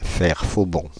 Ääntäminen
IPA : /lɛt daʊn/